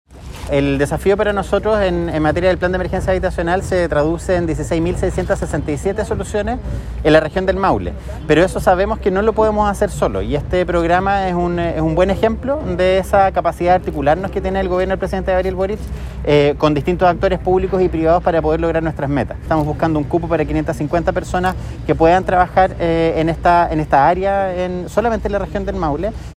DPR-Capacitacion-Construccion_Seremi-Vivienda.mp3